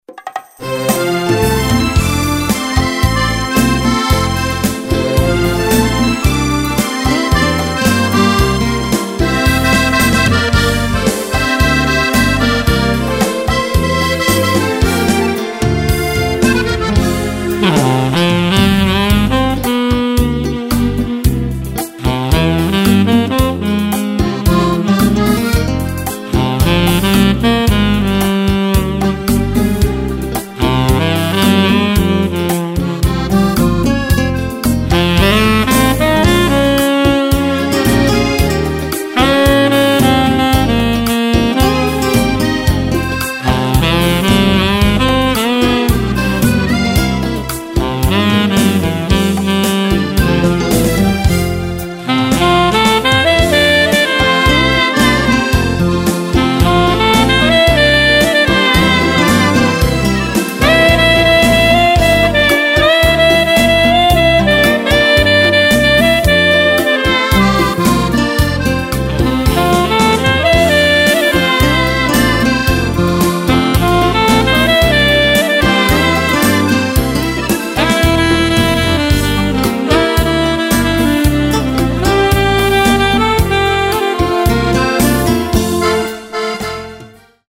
Beguine
Sax